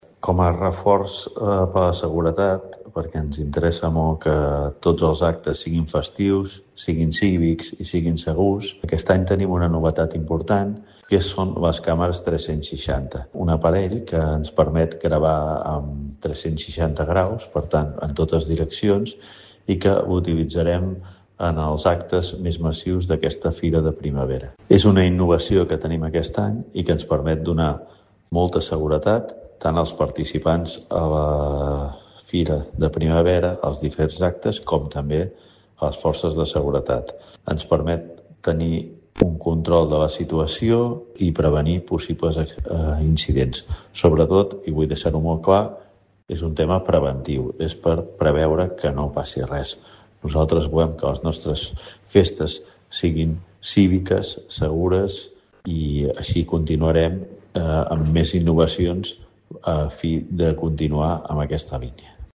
Lluís Sagarra, regidor Seguretat Ciutadana